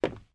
wood_thud.mp3